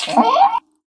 jump.ogg.mp3